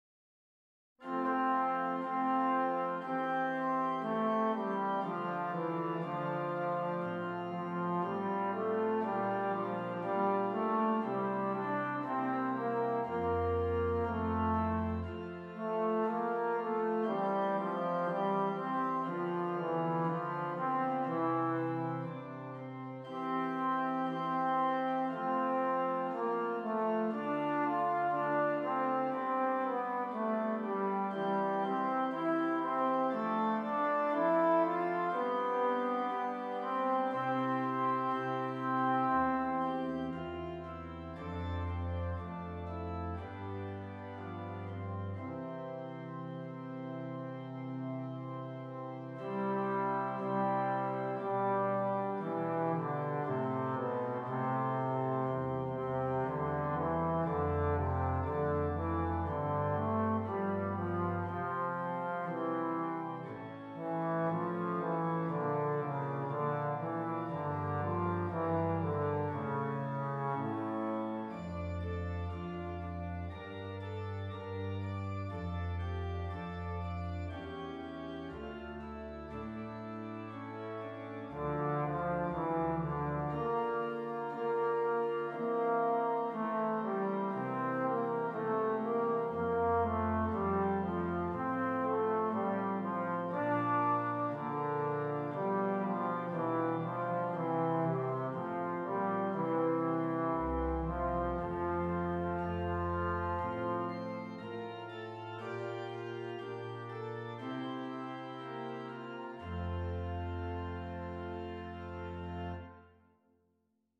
Trombone and Keyboard